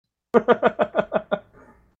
Laugh 1